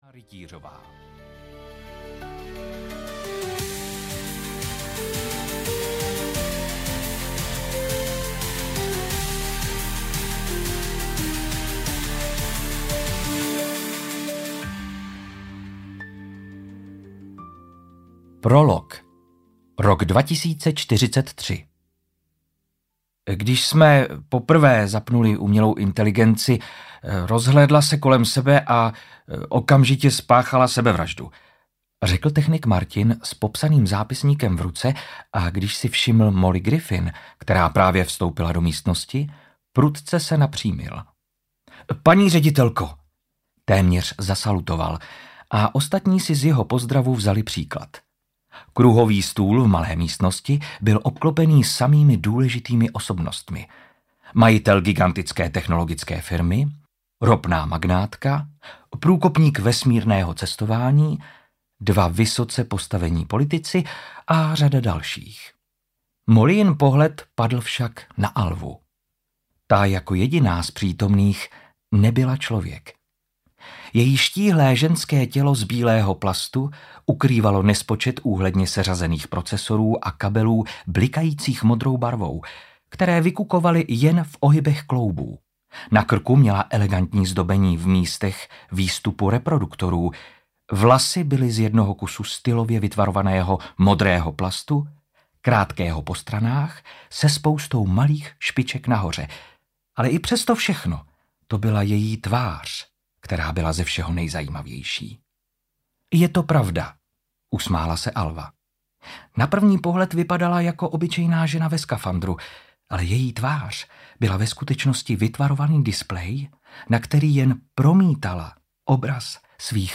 Odkaz lidské mysli audiokniha
Ukázka z knihy